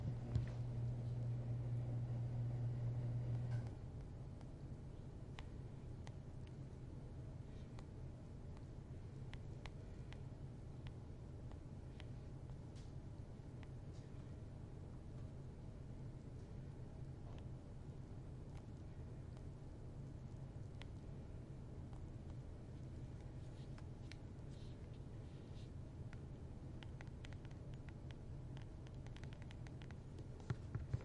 有人在洗手 2
描述：有人在大浴室洗手，靠近水槽记录。 立体声录音 放大Mp3录像机
Tag: 清洗 肥皂 毛巾 饮水机 洗涤 浴室